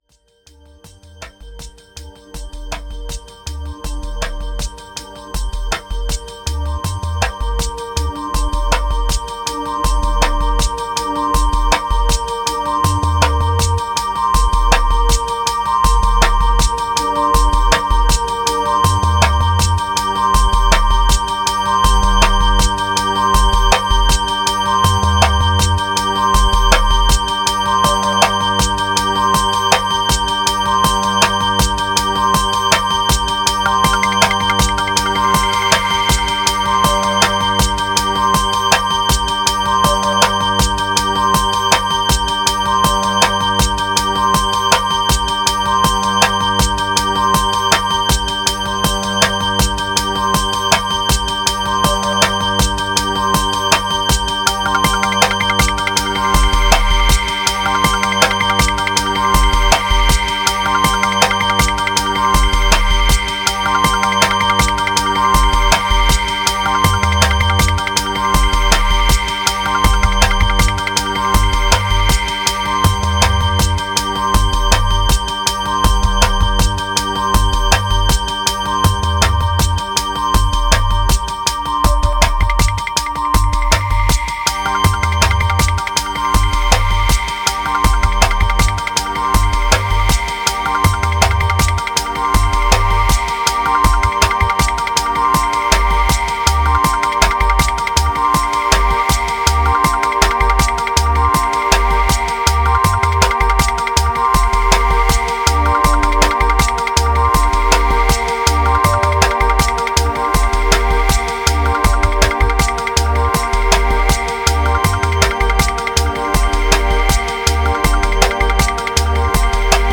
984📈 - 96%🤔 - 80BPM🔊 - 2020-11-07📅 - 1452🌟
Riddim Relief Deviation Love Failures Pattern Dub Apa